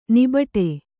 ♪ nibaṭe